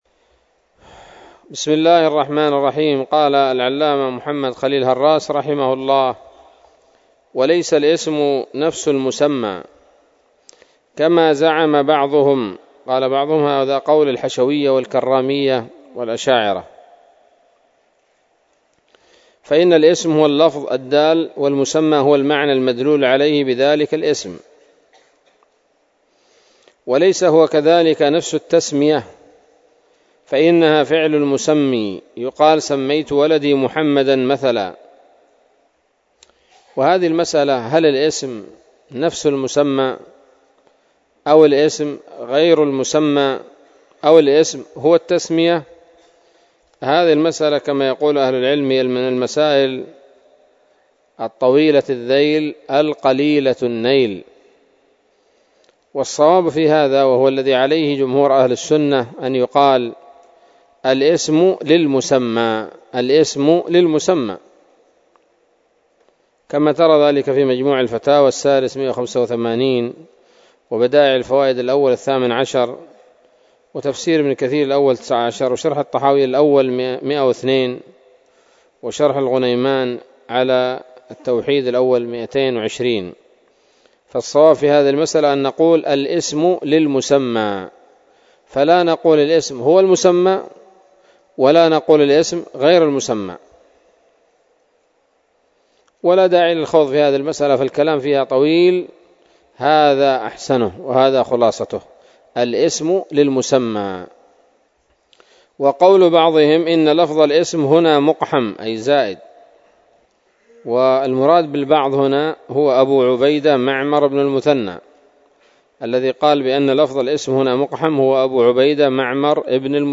الدرس الرابع من شرح العقيدة الواسطية للهراس